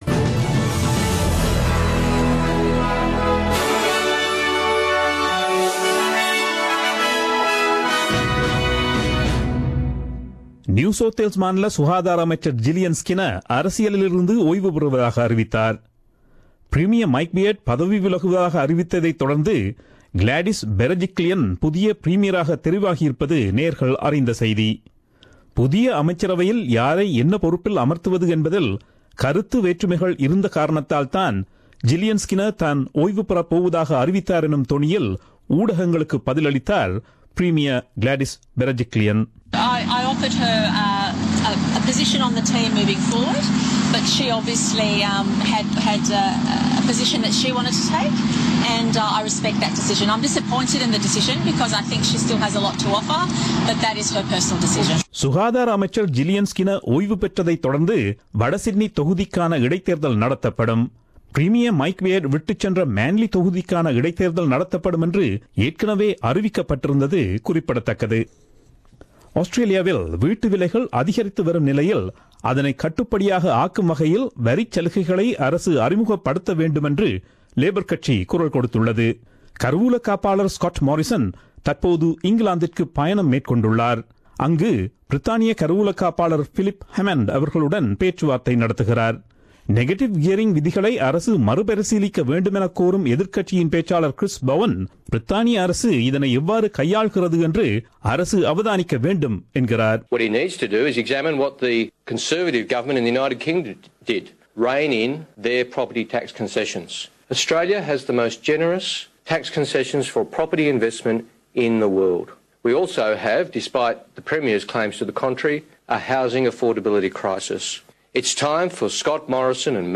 Australian news bulletin aired on Friday 27 Jan 2017 at 8pm.